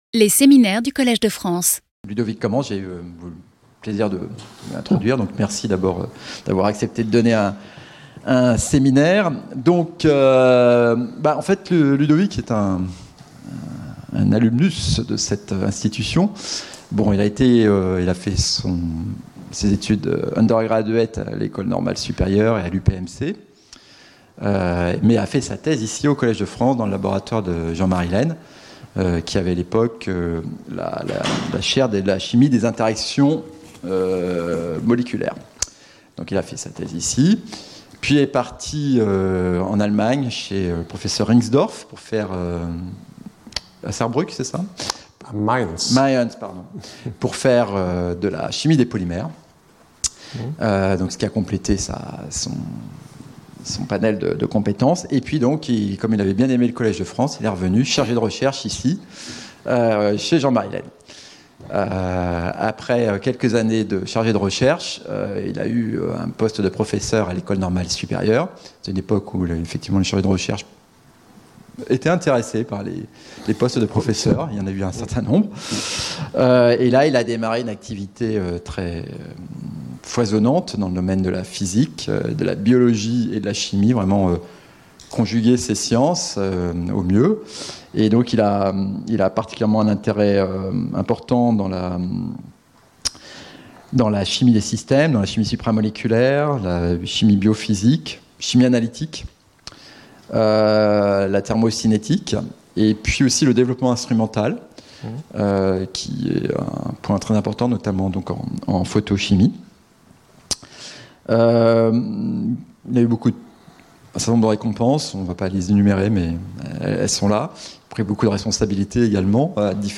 Séminaire